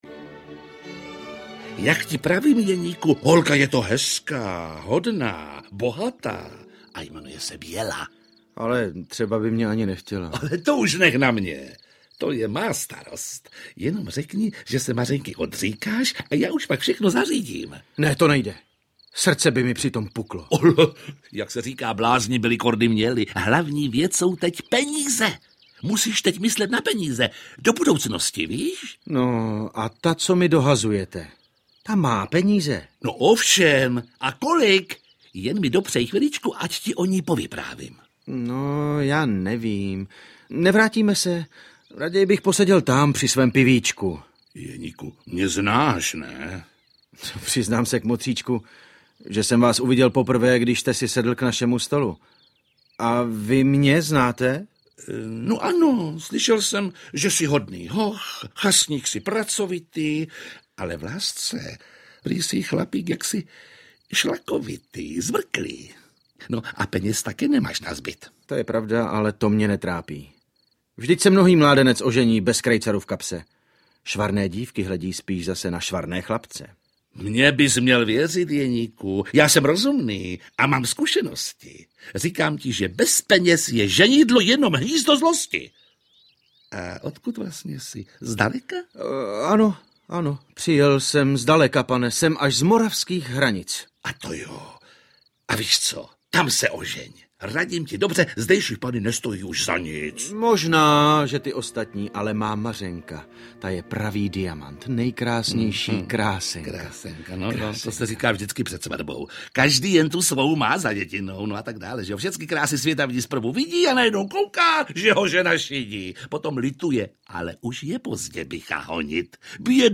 Slavný operní příběh v dramatizaci Jana Jiráně podle původního libreta s ukázkami nejslavnějších hudebních pasáží v podání největších českých operních pěvců!